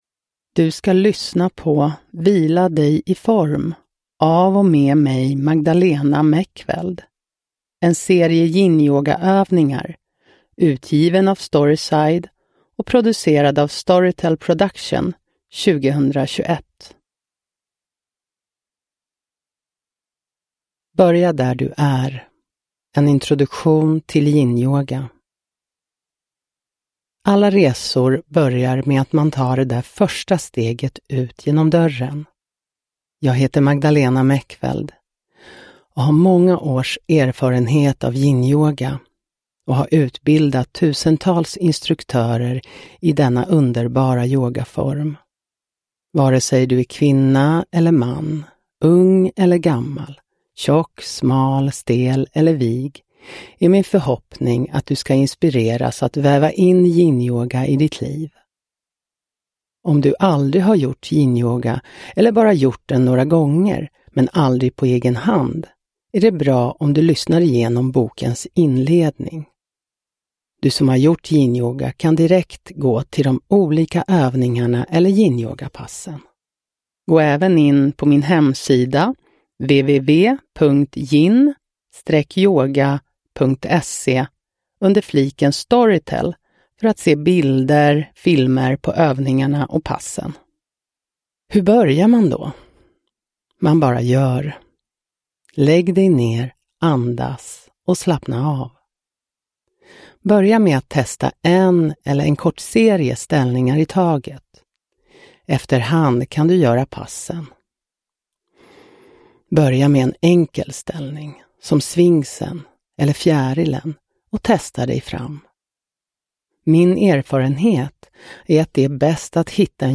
Börja där du är - Introduktion – Ljudbok – Laddas ner